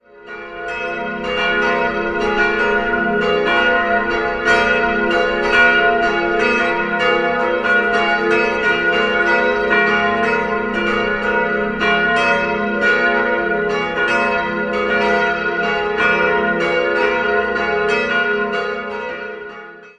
Um 1741 erfolgte ein Umbau, im Rahmen dessen das Gotteshaus auch seine überaus prächtige, farbenfrohe Ausstattung erhielt. 3-stimmiges Geläut: f'-as'-b' Alle Glocken wurden von Johann Hahn in Landshut gegossen: Die mittlere 1922, die beiden anderen 1950.